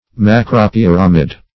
Search Result for " macropyramid" : The Collaborative International Dictionary of English v.0.48: Macropyramid \Mac`ro*pyr"a*mid\, n. [Macro- + pyramid.]